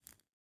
Minecraft Version Minecraft Version snapshot Latest Release | Latest Snapshot snapshot / assets / minecraft / sounds / block / candle / ambient1.ogg Compare With Compare With Latest Release | Latest Snapshot